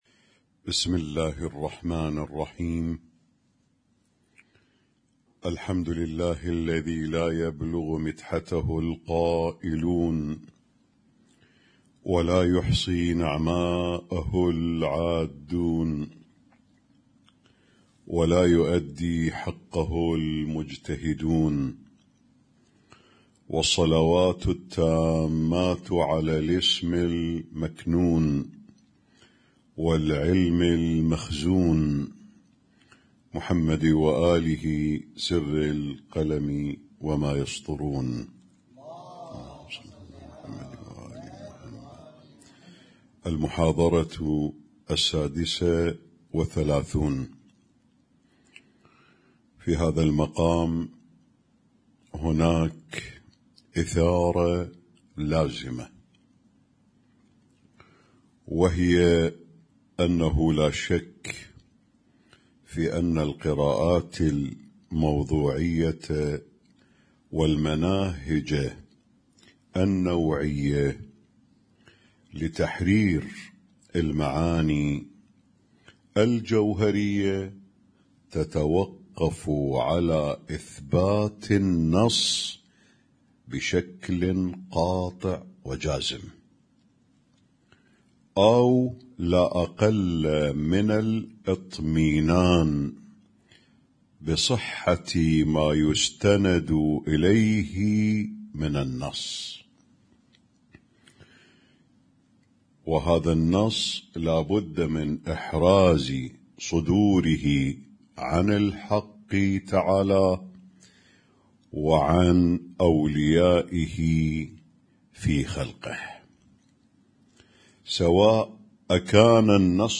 اسم النشيد:: الدرس السادس والثلاثون - إثباتِ مصونيّةِ النصّ من التحريف